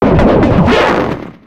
Cri de Darumacho Mode Normal dans Pokémon X et Y.